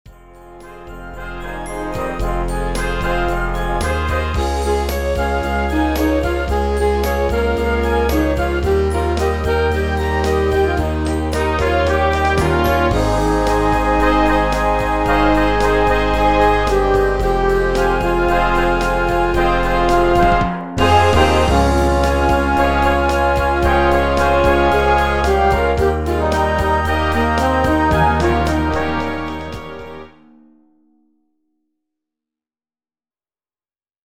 Concert Band Edition